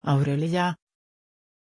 Aussprache von Aurelia
pronunciation-aurelia-sv.mp3